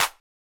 CardiakClap.wav